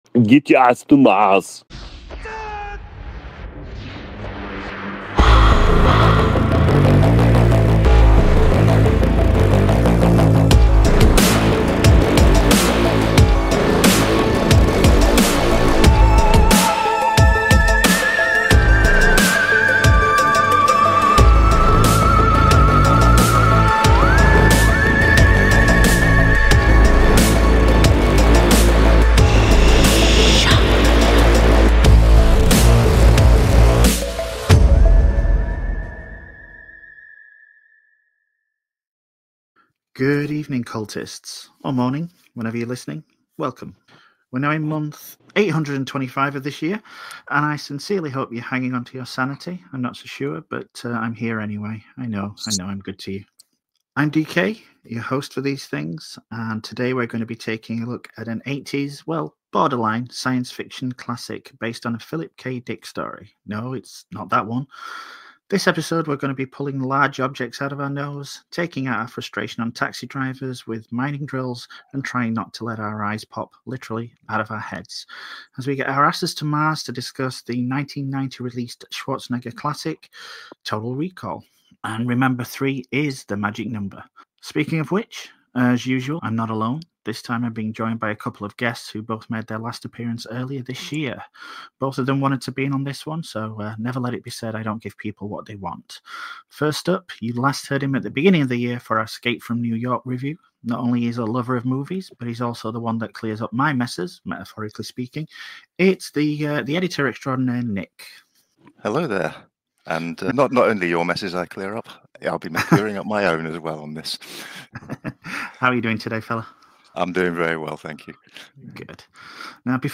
An Interview With Catherine Mary Stewart – Media Matters Podcast Network – Podcast – Podtail